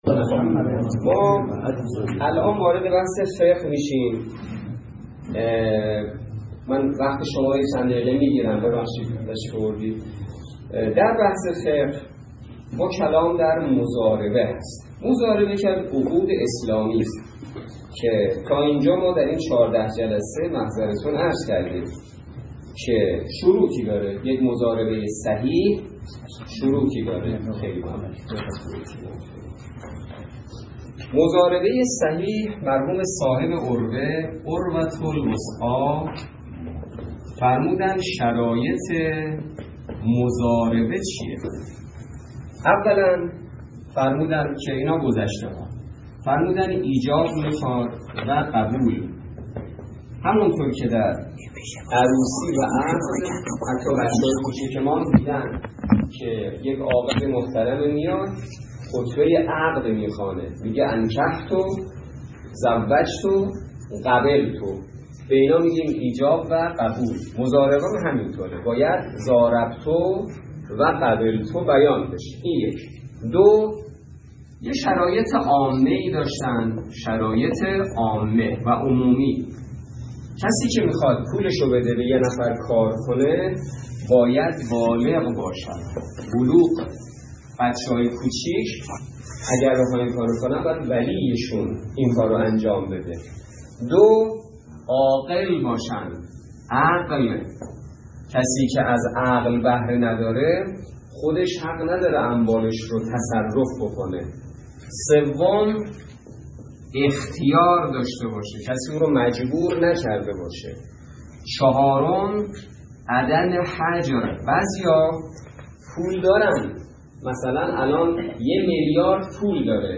درس فقه